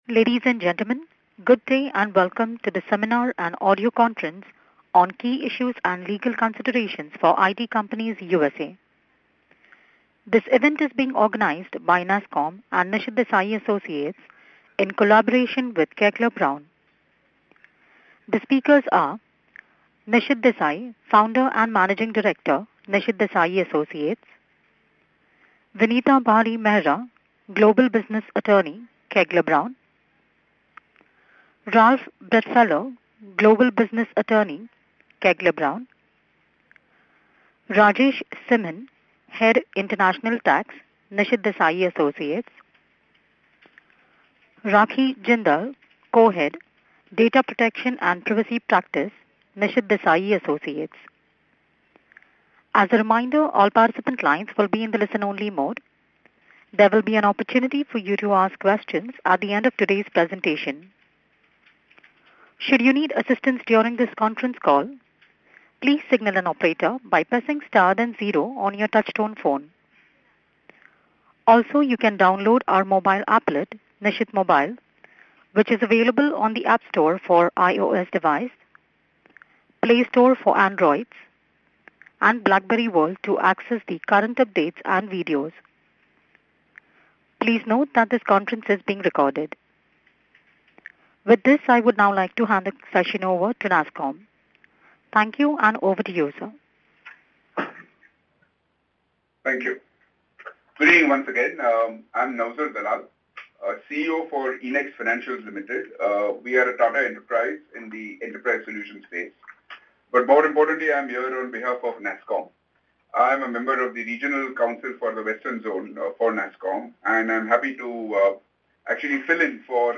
Seminar: Key Issues and Legal Considerations for IT Companies Doing Business in USA (Wednesday, January 14, 2015)